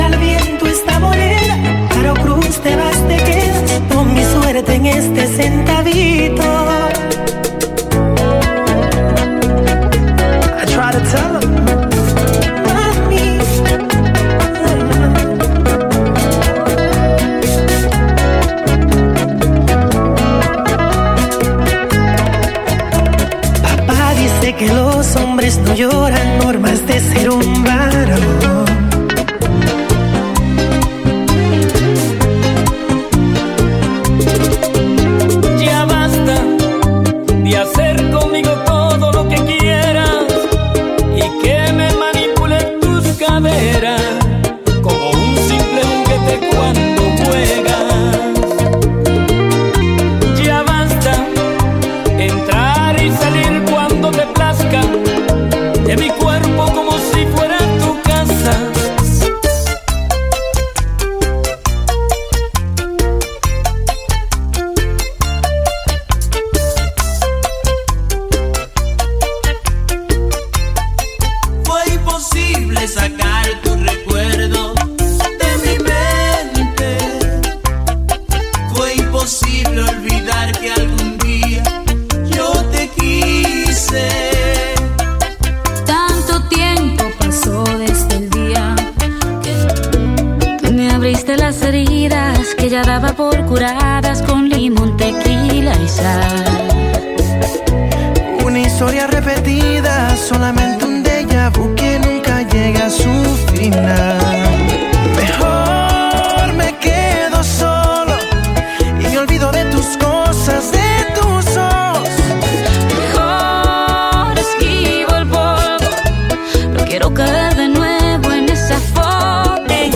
Bachata
Best of Bachata